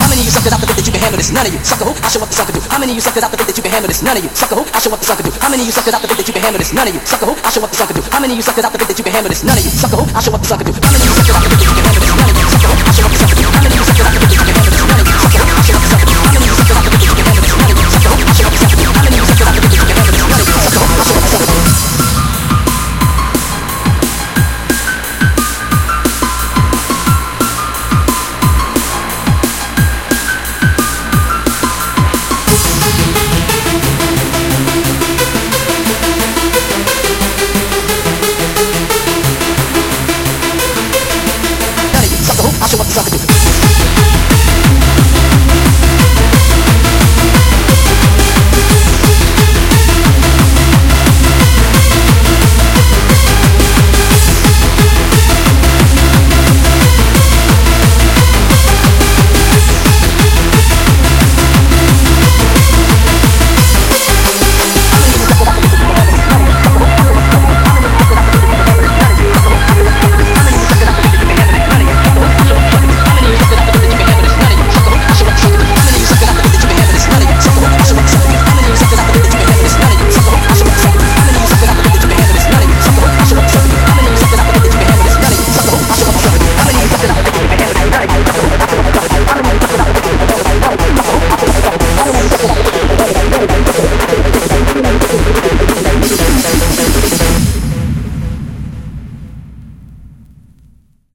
BPM177
Audio QualityPerfect (High Quality)